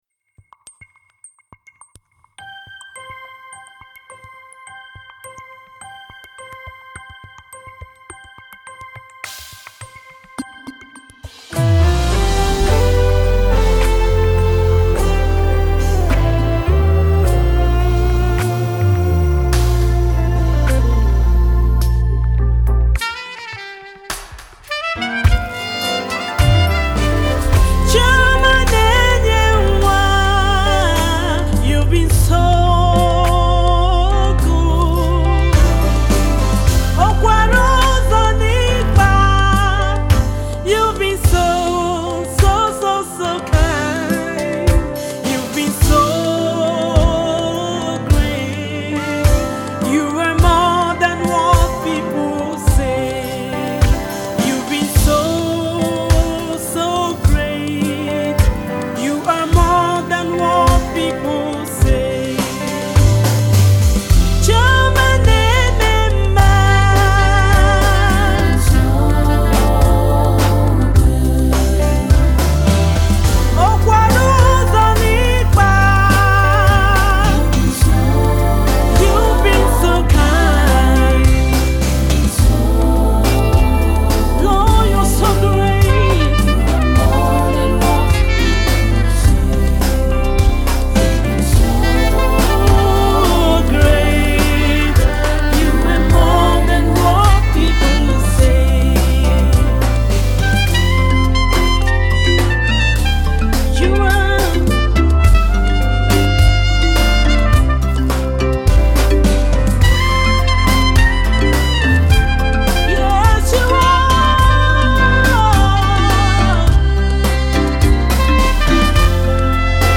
UK-based Nigerian gospel recording artist and worship leader
worship piece
singer-songwriter and psalmist